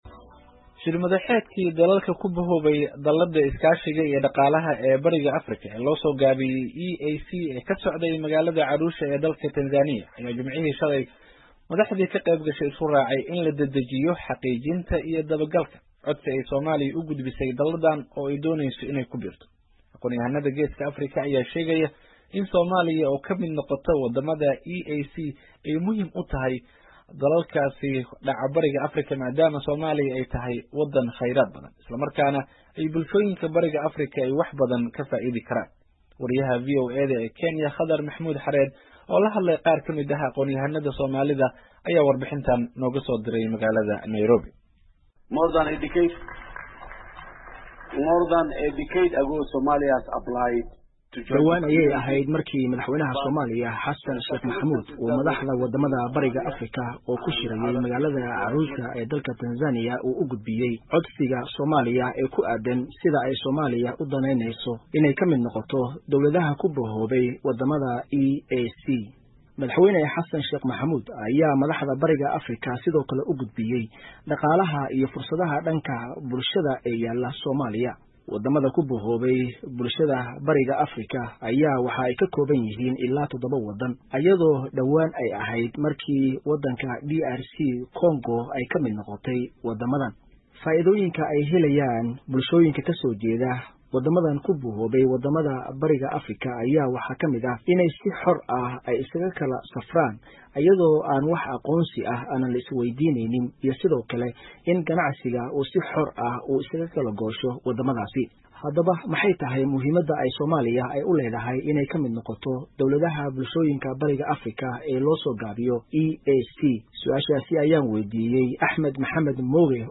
oo la hadlay qaar kamid ah aqoonyahannadda Soomaaliyeed ayaa warbixinta inooga soo diray Nairobi.